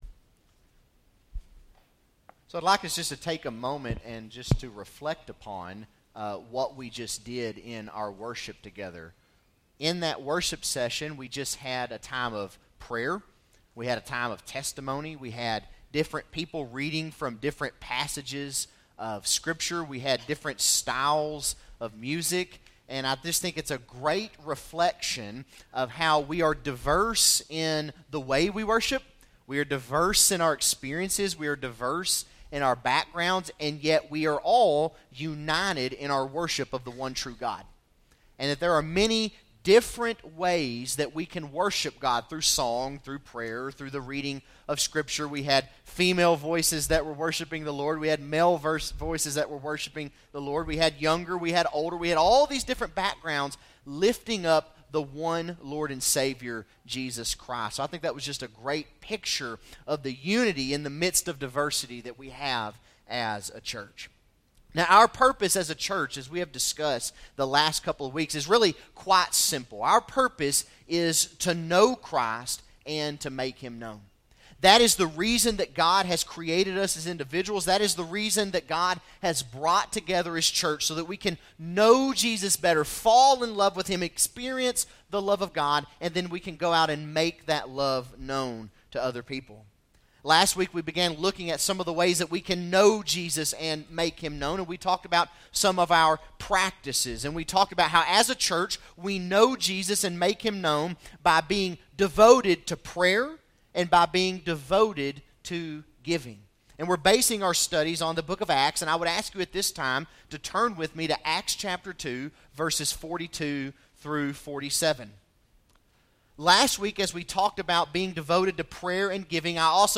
Bible Text: Acts 2:42-47 | Preacher